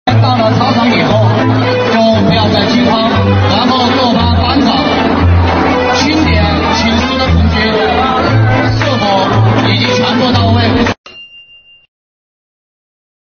泸县二中学生紧急有序疏散
到操场避险